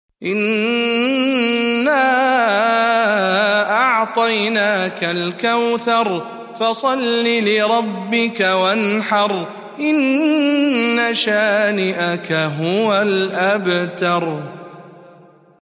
107. سورة الكوثر برواية شعبة عن عاصم